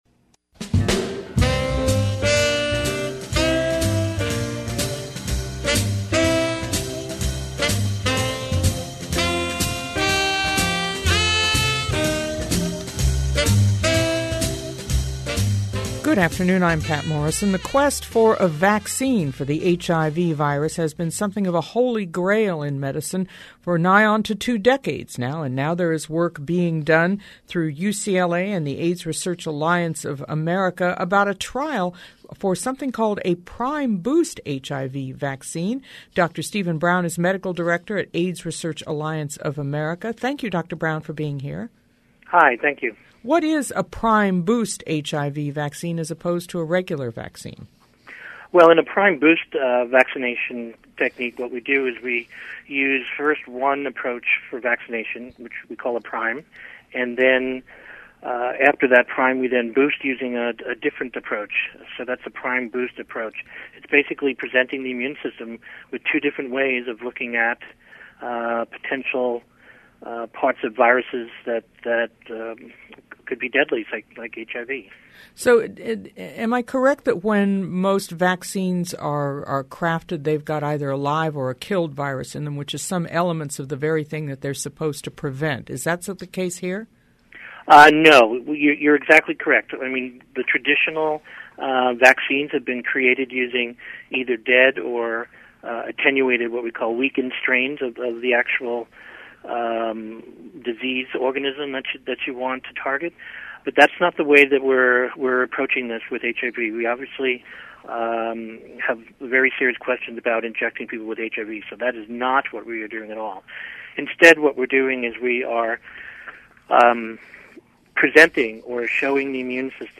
Download the Official Press Release 10/29/09 AIDS Research Alliance starts new vaccine study. KPCC 89.3 Interview